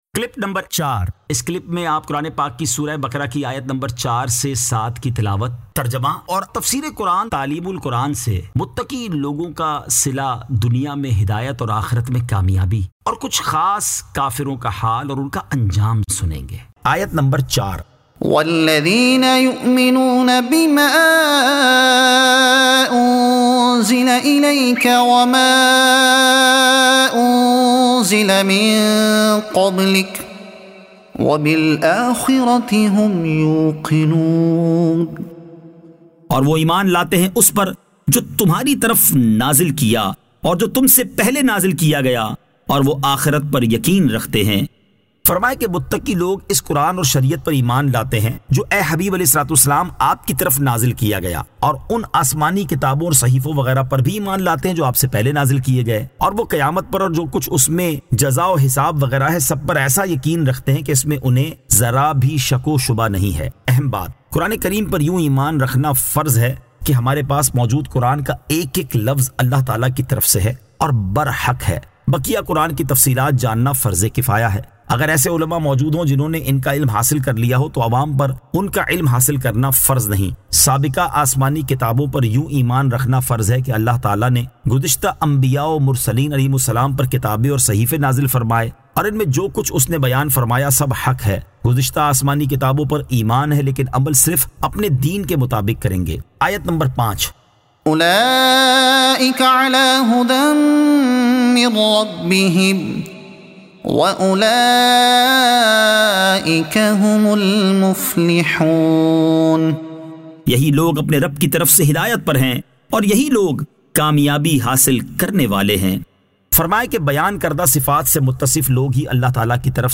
Surah Al-Baqara Ayat 04 To 07 Tilawat , Tarjuma , Tafseer e Taleem ul Quran